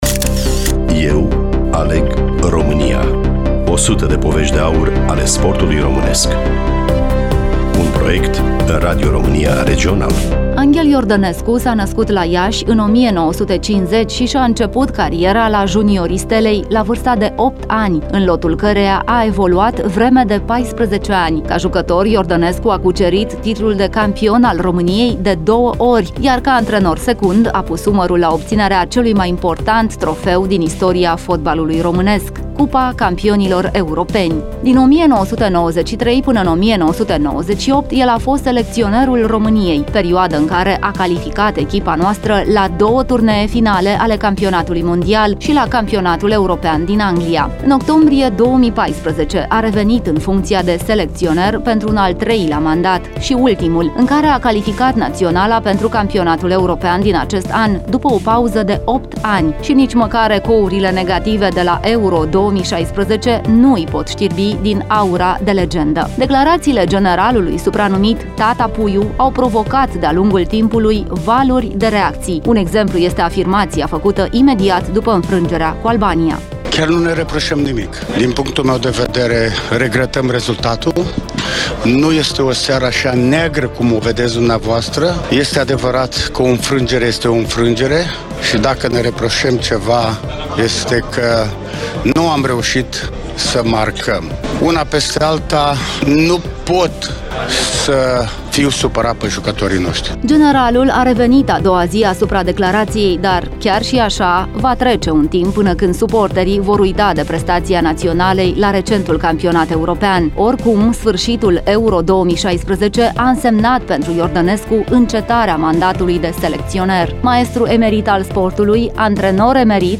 Redactor / voice over: